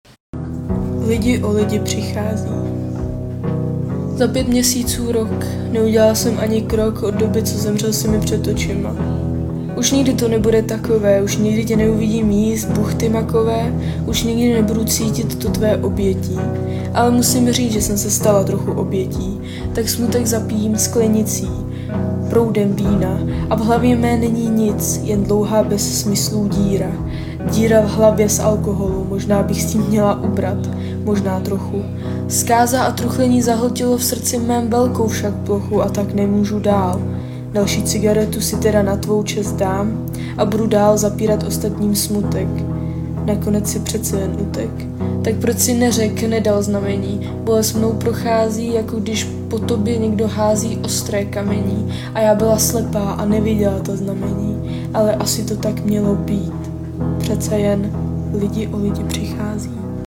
Básně » Ze života
Hlas je krásný a předčítáš nádherně.
Zasáhlo do srdce, spojení textu, hudby, ten přednes, krásný, díky za odvahu sdílet, když lidi o lidi přichází
Líbi se mi jak báseň, tak ten 'odfláknutý' - syrový přednes.